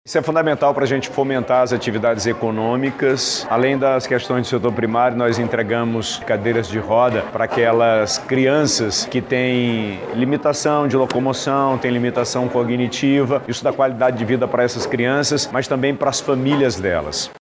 Durante as entregas o governador Wilson Lima ressaltou que as medidas tem objetivo de impulsionar o setor e reduzir as situações de vulnerabilidade.